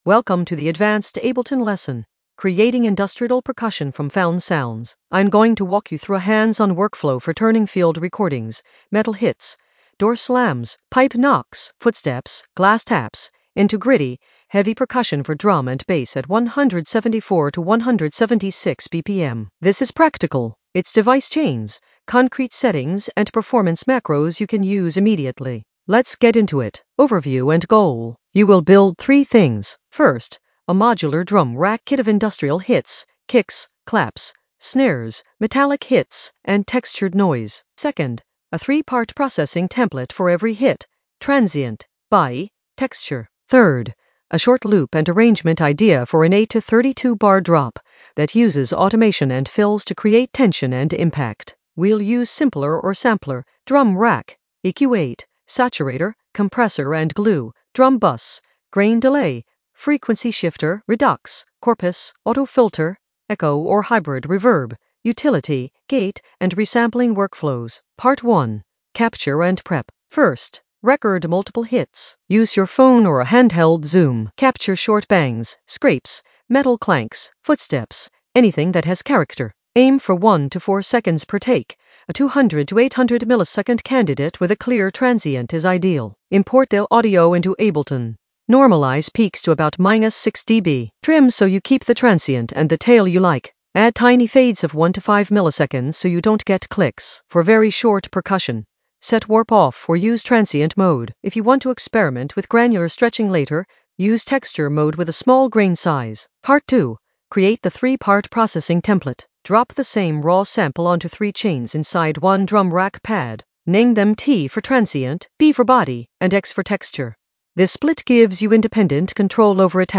An AI-generated advanced Ableton lesson focused on Creating industrial percussion from found sounds in the Sound Design area of drum and bass production.
Narrated lesson audio
The voice track includes the tutorial plus extra teacher commentary.